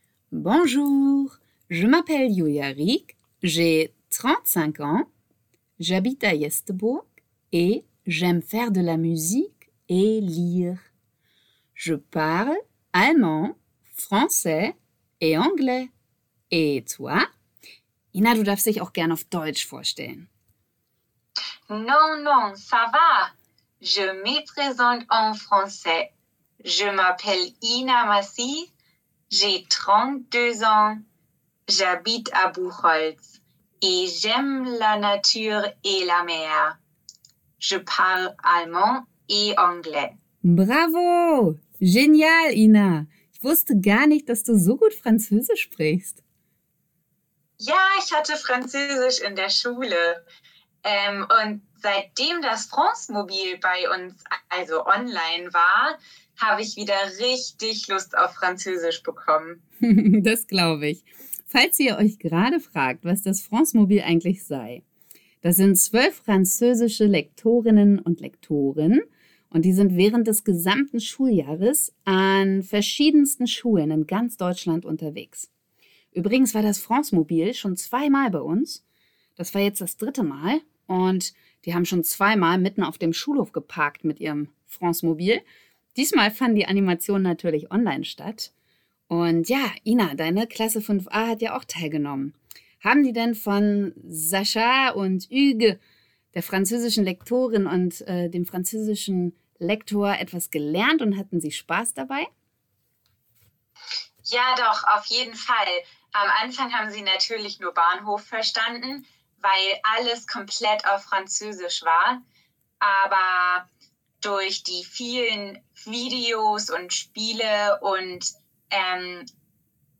Telefon-Interview-FranceMobil.mp3